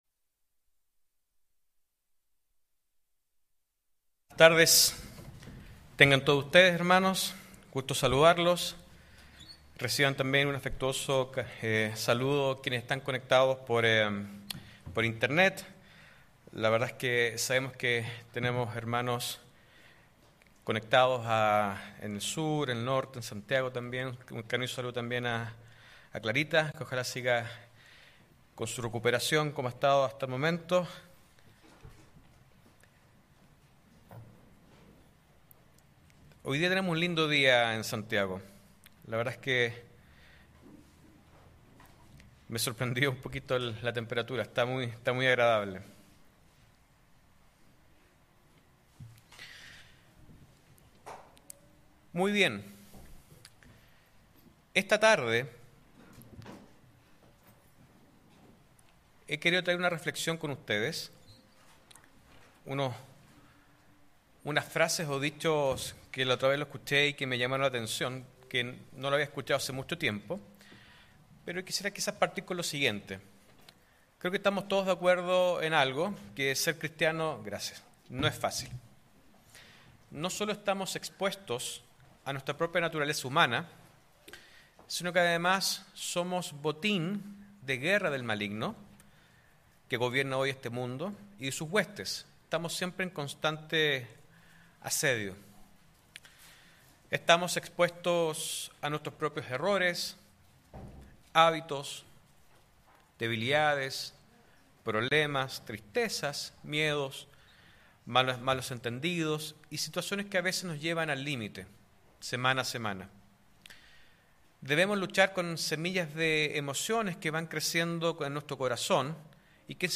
¿Cómo alcanzar el correcto equilibrio?. Mensaje entregado el 17 de junio de 2023.